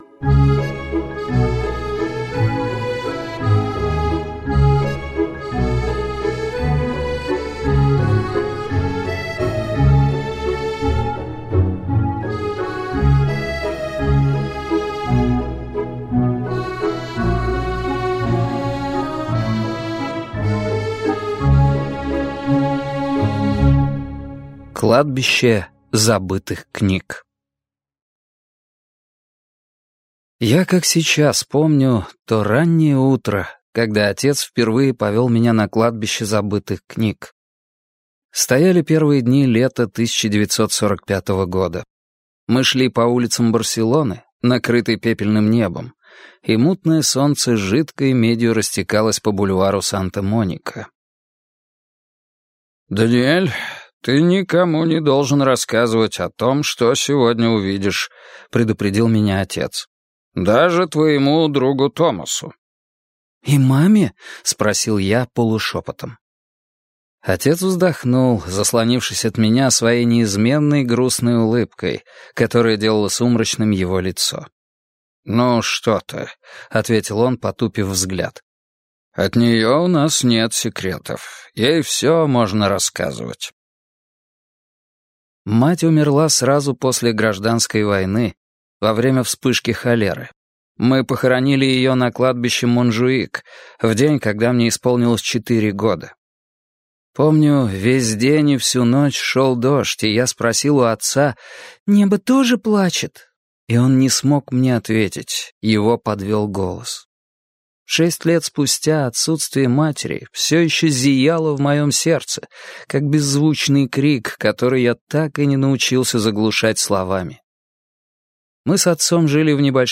Аудиокнига Тень ветра | Библиотека аудиокниг